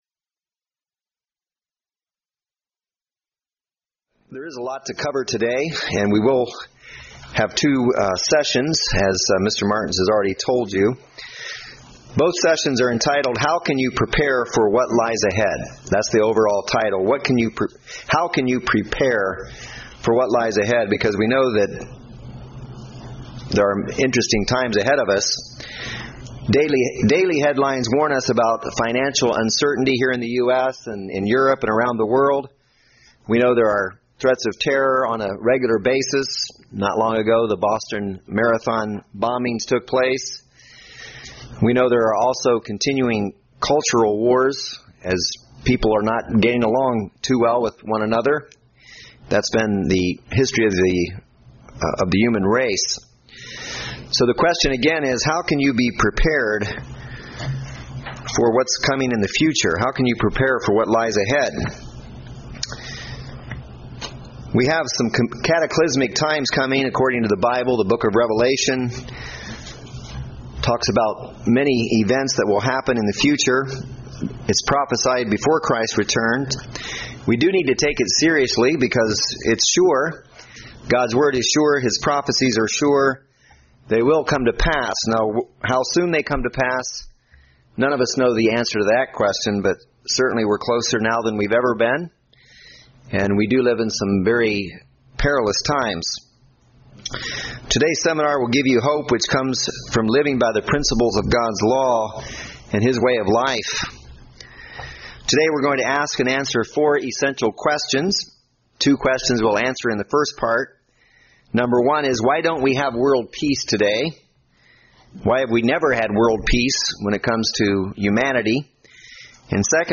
Learn from these seminars how you can prepare for the challenges and opportunities that lie ahead. Both seminar sessions are in this presentation with a 15 second audio gap in-between.